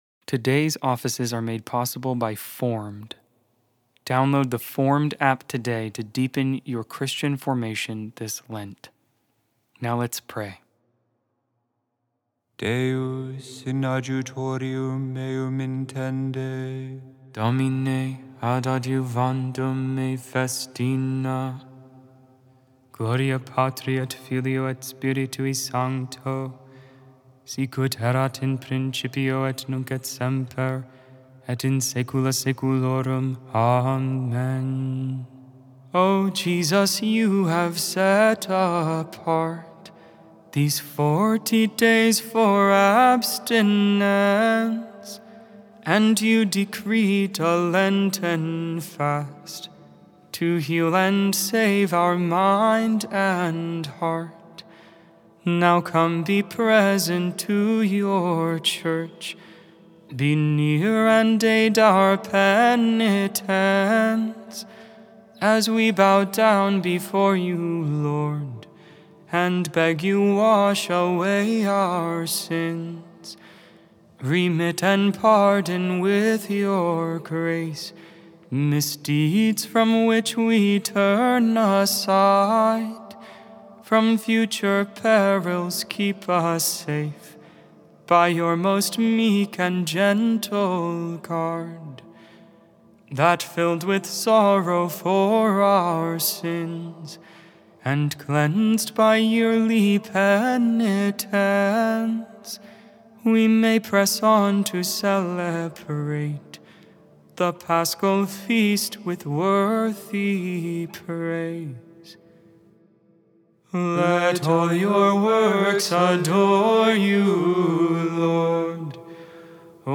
4.11.25 Vespers, Friday Evening Prayer of the Liturgy of the Hours